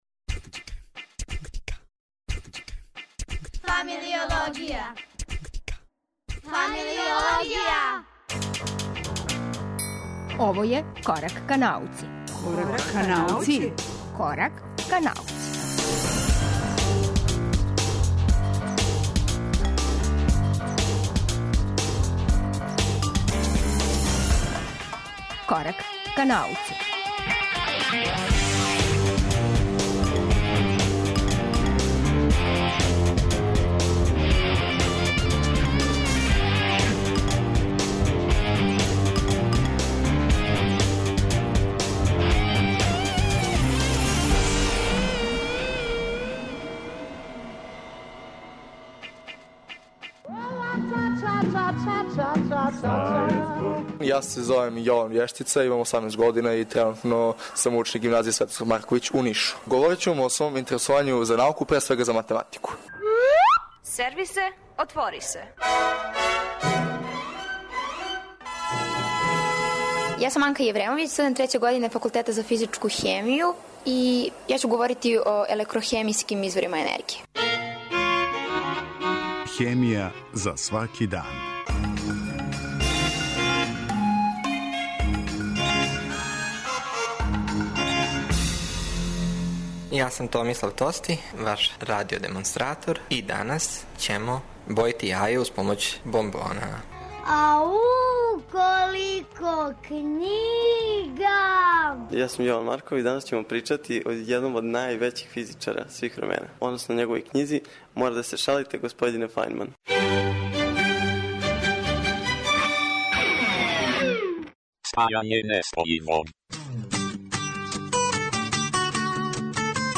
Четири музичке нумере, пет разговора и шест немогућих ствари, постали су мера нашег заједничког корачања ка науци које се држимо и овог четвртка.